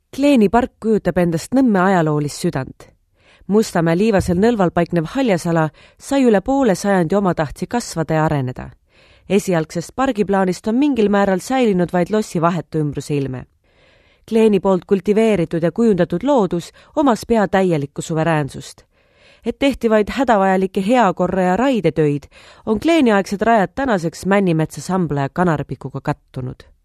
Estonian, Female, 20s-40s